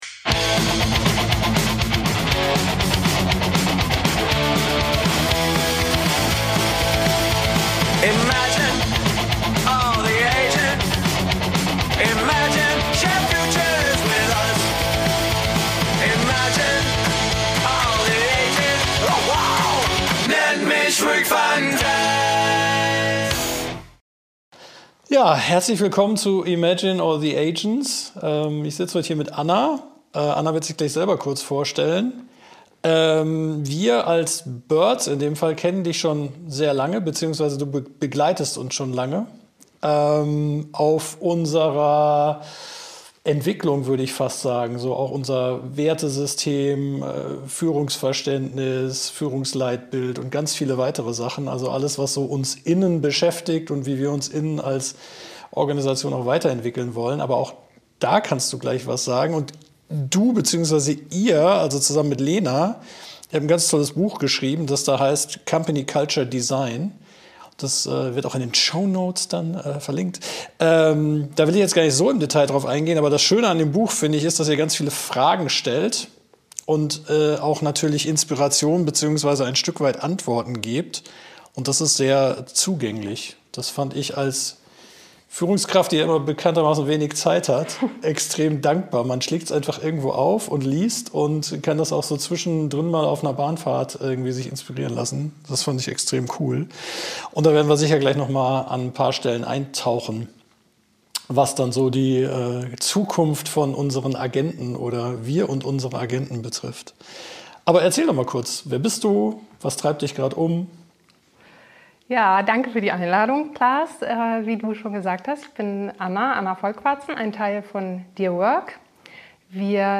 Ein tiefgründiges Gespräch über Kulturwandel, das Loslassen alter Gewohnheiten und den Mut, Arbeit radikal neu zu denken.